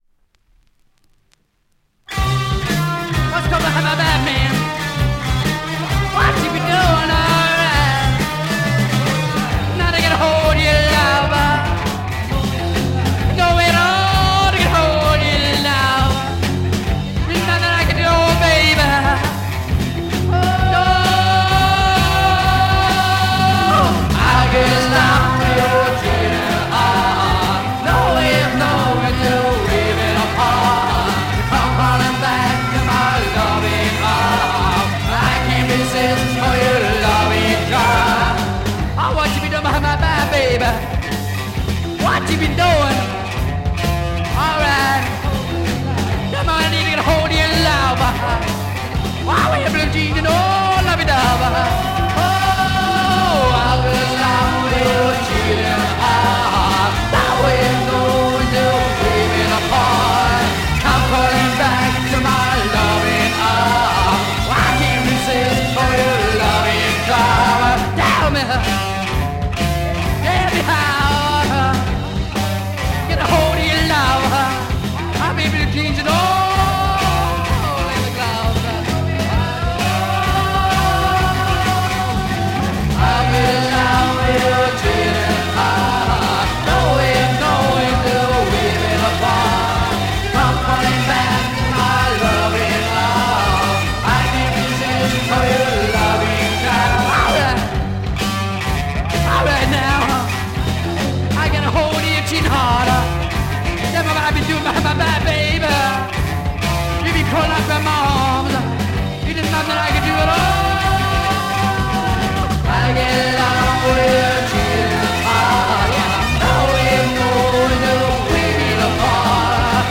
German freakbeat Mod punk single